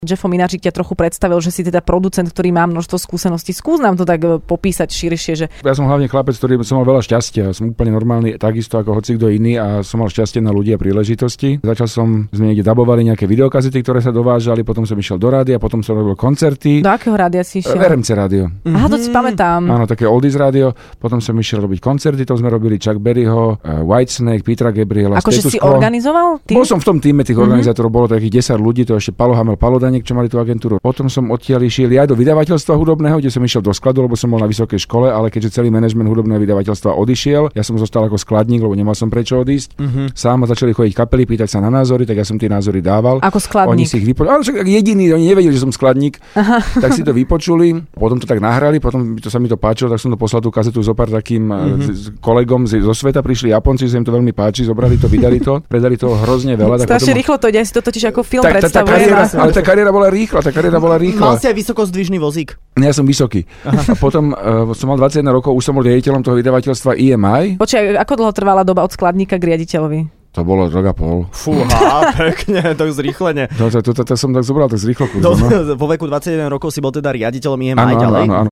V Rannej šou sa dnes rozprávalo o šou Česko-Slovensko má talent a porotca Jaro Slávik v štúdiu, okrem iného, porozprával o svojej raketovej kariére a živote...